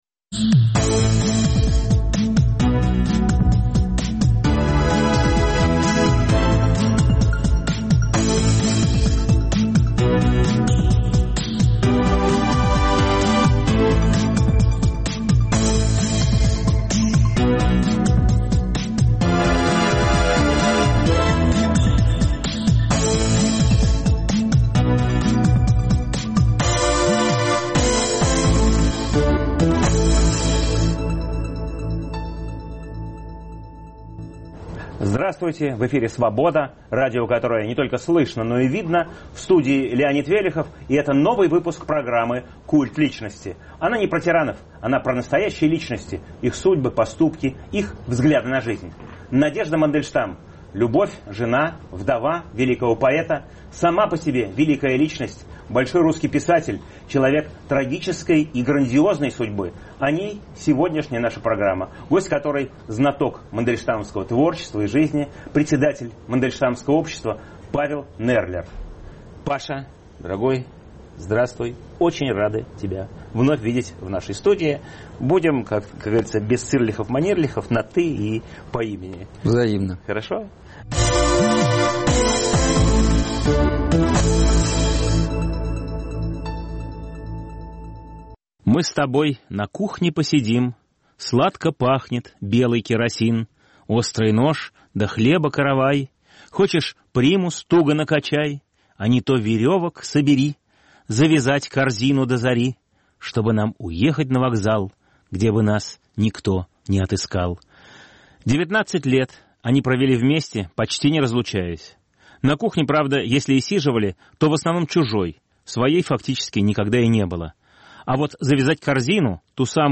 Надежда Мандельштам – вдова великого поэта, которая сохранила для нас его стихи, а сама написала книгу о своей эпохе, которую Иосиф Бродский назвал лучшей прозой ХХ века. В студии